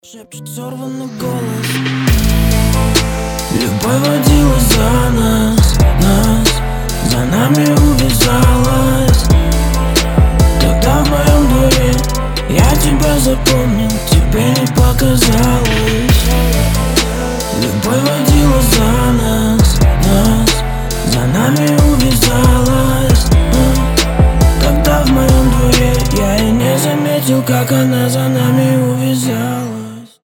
мужской вокал
лирика
русский рэп